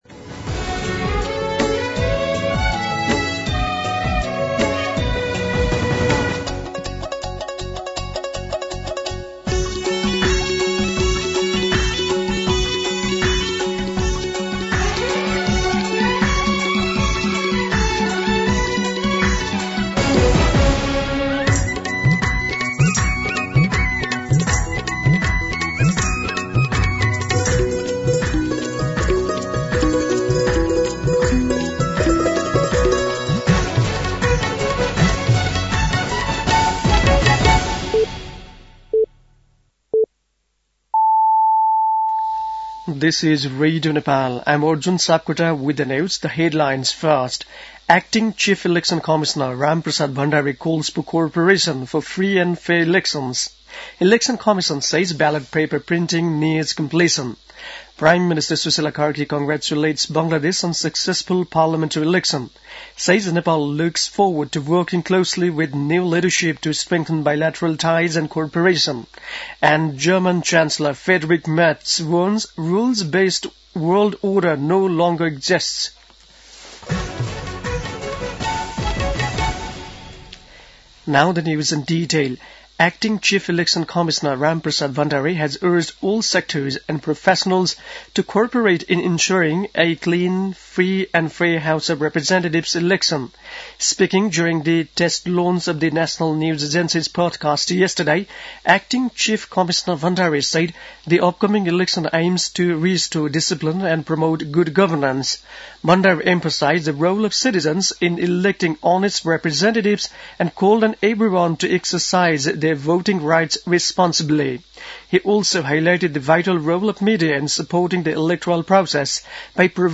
दिउँसो २ बजेको अङ्ग्रेजी समाचार : २ फागुन , २०८२